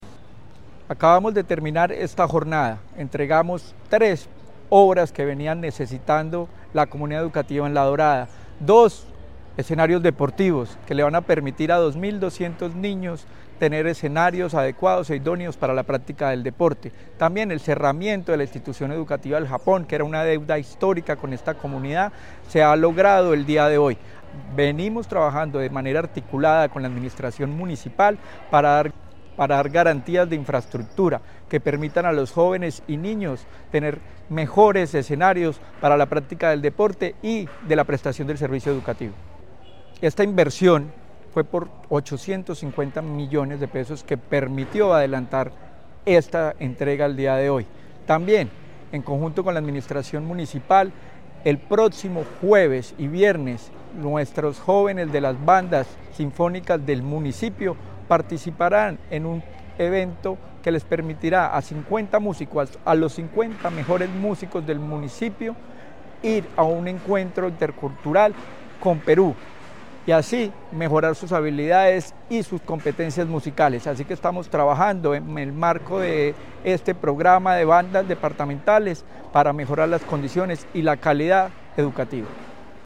Luis Herney Vargas Barrera, secretario de Educación de Caldas